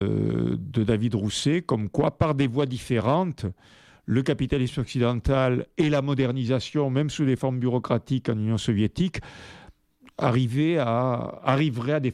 Echos du Capricorne est une émission réalisée en direct, bilingue (français et malgache). C’est une émission d’information et de dialogue à propos des réalités culturelles, sociales, économiques et politiques de Madagascar et de cette région de l’Océan Indien.